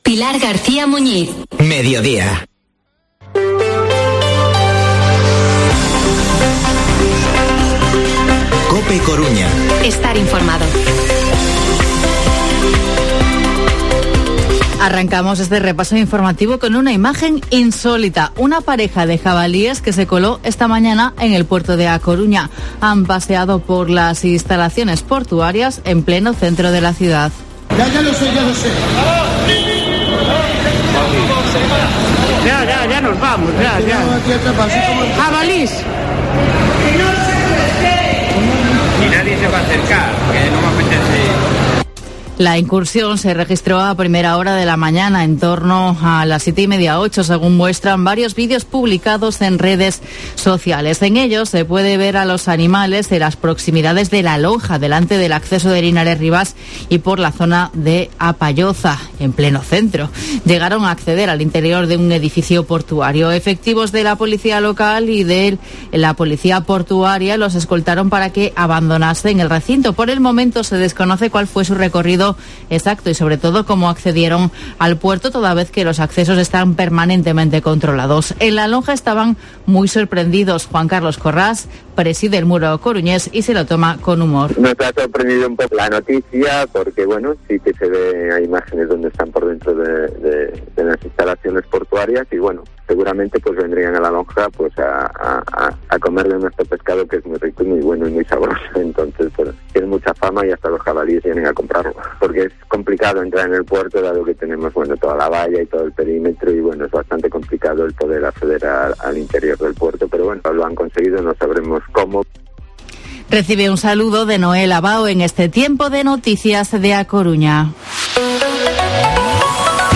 Informativo Mediodía COPE Coruña miércoles, 27 de octubre de 2021 14:20-14:30